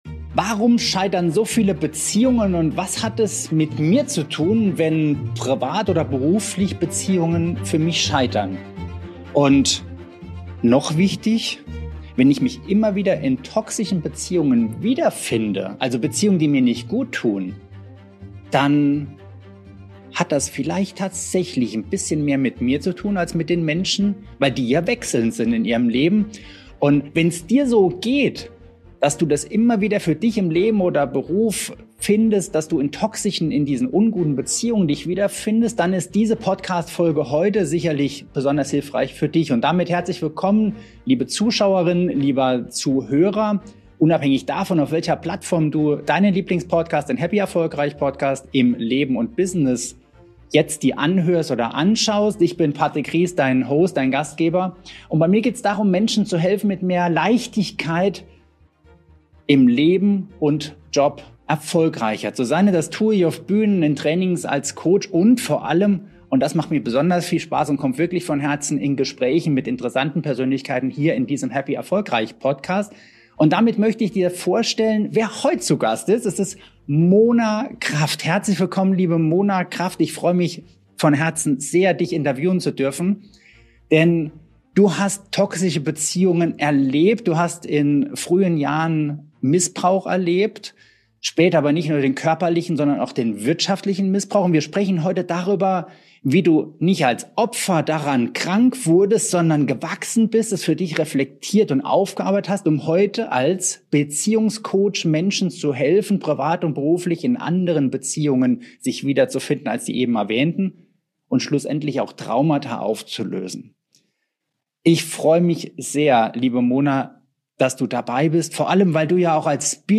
Du erfährst in diesem Interview: Warum so viele Beziehungen zum Scheitern verurteilt sind Weshalb wir im Außen oft etwas suchen, das wir nur in uns selbst finden können Auf welche Weise toxische Beziehungen auch unseren beruflichen Erfolg sabotieren Welche Schritte dir helfen, alte Verletzungen zu heilen und gesunde, erfüllende Beziehungen aufzubauen Diese Episode ist für dich, wenn du dich immer wieder in destruktiven Beziehungsmustern wiederfindest – ob privat oder im Business – und lernen möchtest, wie du echte Verbundenheit, Selbstsicherheit und Freiheit in deinem Leben etablierst.